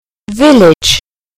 Dzięki temu, że są one odczytywane, poznajemy prawidłową wymowę.